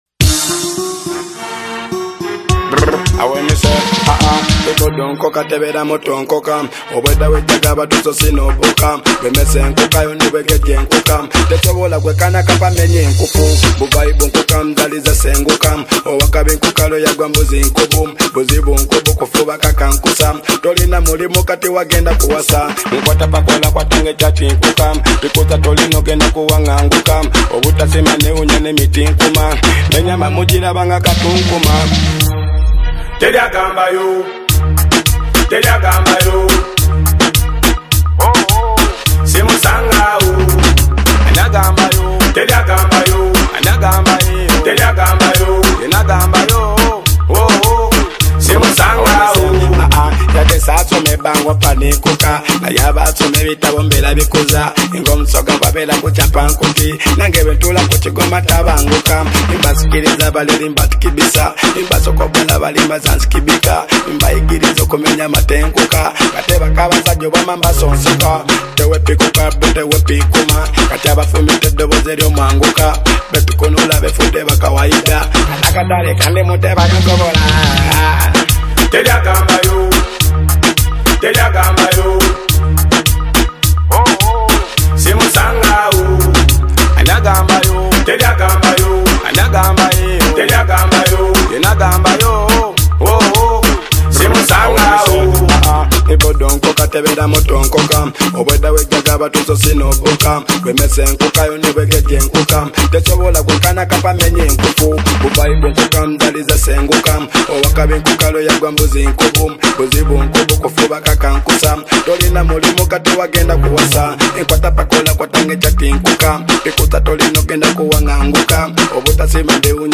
it’s a loud, confident statement of dominance.